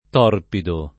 t0rpido] agg. — parola entrata in it. fin dal ’500 per via dòtta, riproducendo il lat. torpidus e prendendo l’-o- aperto della pn. scolastica (in questo caso, uguale alla pn. classica dell’-o-, breve per natura) — preval. oggi più o meno un -o- chiuso nell’uso della Tosc. e dell’It. centr., prob. per attraz. di torbido, data la vicinanza di suono (un po’ come nel caso di torvo e torbo)